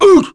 Gladi-Vox_Damage_kr_01.wav